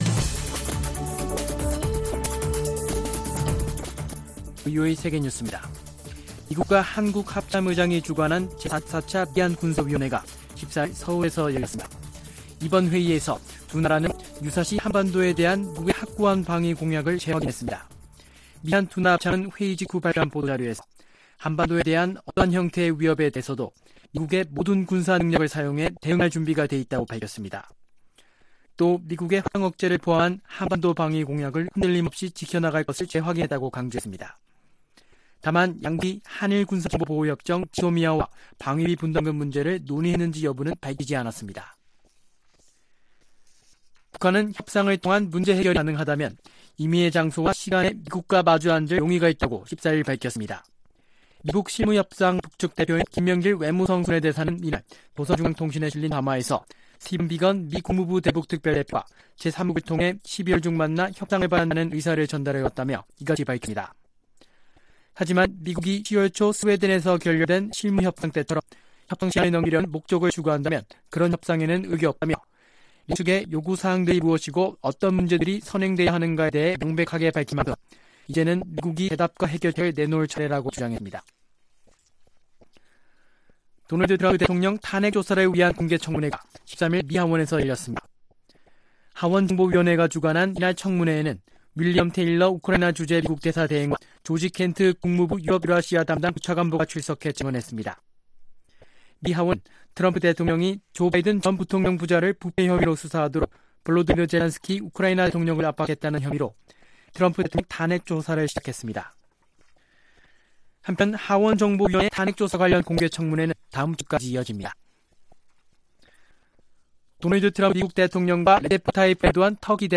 VOA 한국어 아침 뉴스 프로그램 '워싱턴 뉴스 광장' 2019년 11월 15일 방송입니다. 마크 에스퍼 미 국방장관이 북한과의 비핵화 협상을 위한 미-한 연합군사훈련의 변경 가능성을 밝혔습니다. 북한이 최근 잇따른 담화를 통해 ‘연말 시한’을 강조하는 것은 단순한 엄포가 아니라고 미국 전문가들이 밝혔습니다.